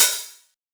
• Muffled Open Hi Hat Sample B Key 21.wav
Royality free open hi hat tuned to the B note. Loudest frequency: 7692Hz
muffled-open-hi-hat-sample-b-key-21-tGZ.wav